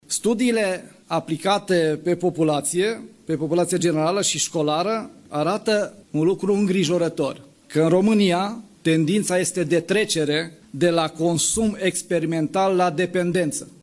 Ministrul de Interne, Lucian Bode, spunea marí că a cerut o analiză la nivelul ministerului pentru eficientizarea activităţii de prevenire şi combatere a consumului de droguri>
08iun-07-Bode-despre-droguri-.mp3